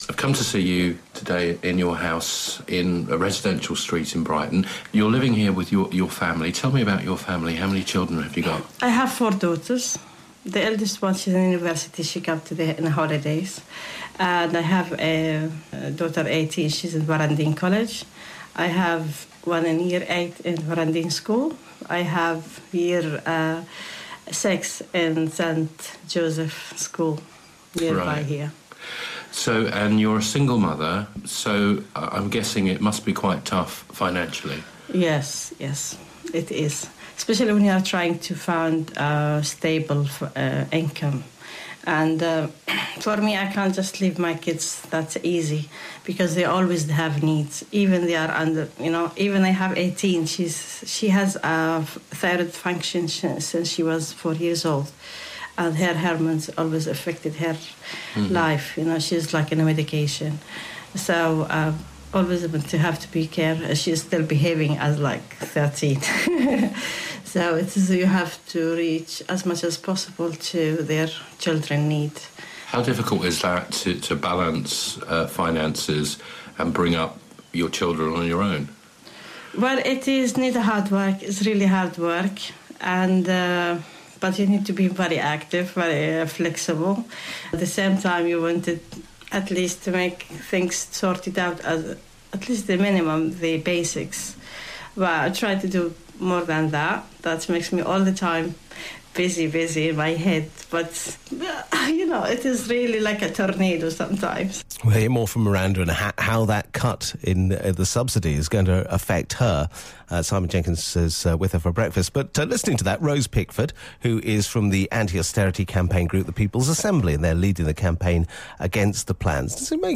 We also heard from campaigner